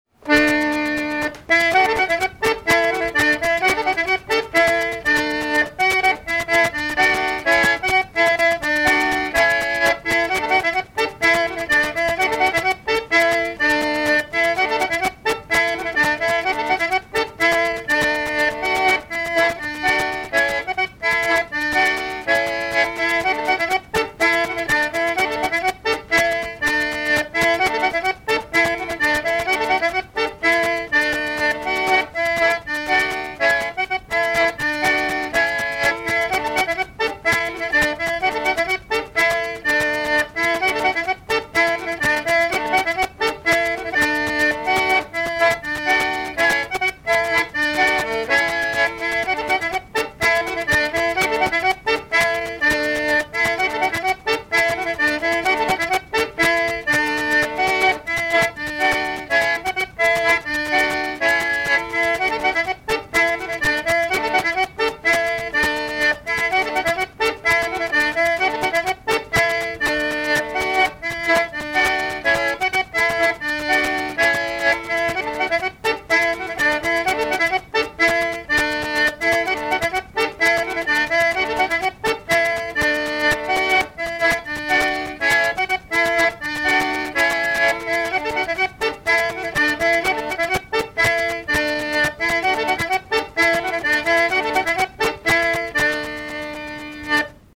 danse : ronde : demi-tour
Répertoire d'airs à danser
Pièce musicale inédite